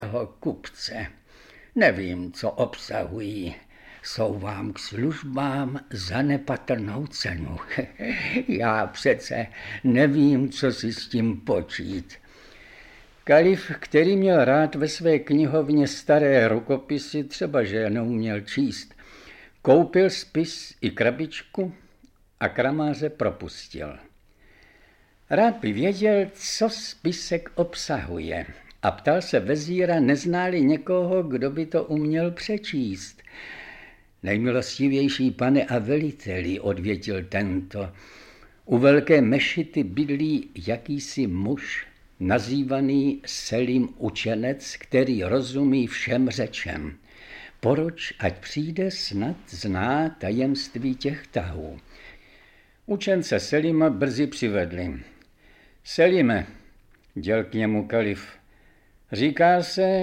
Audiobook
Read: František Smolík